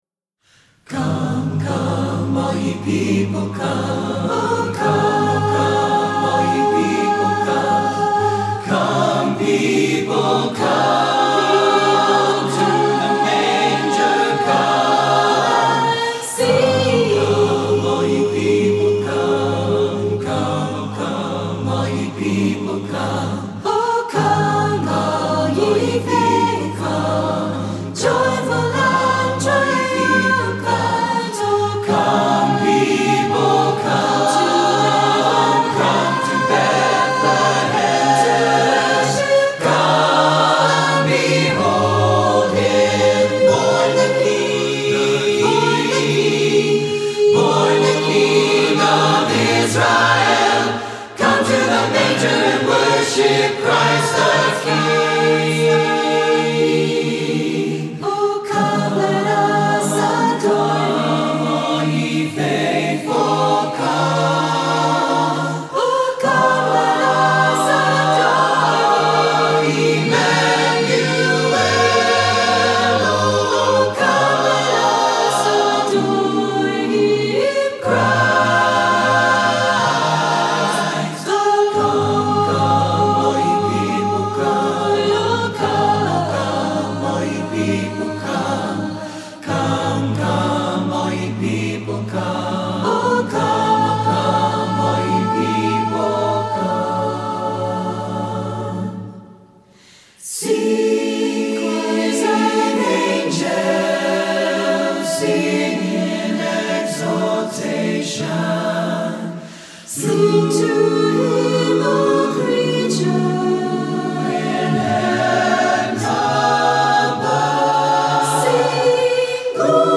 Voicing: SSATBB